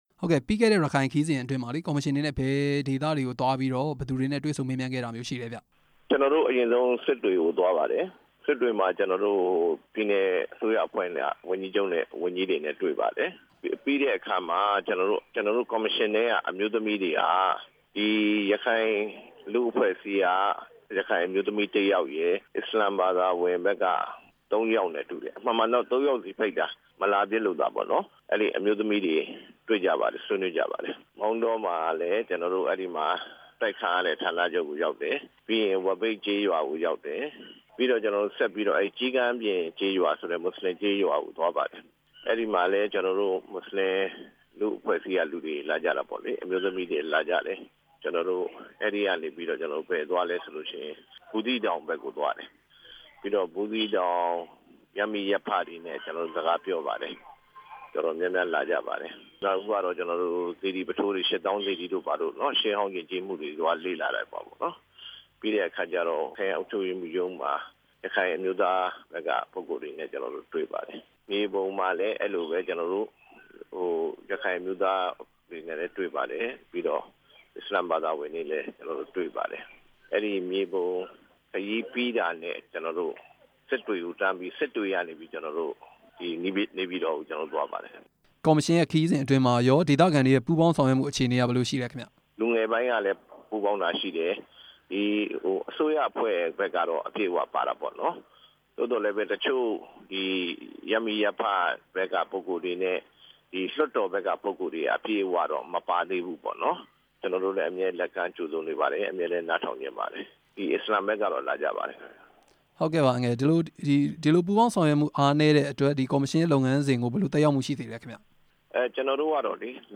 ရခိုင်အကြံပေးကော်မရှင်အဖွဲ့ဝင် ဦးအေးလွင် နဲ့ မေးမြန်းချက်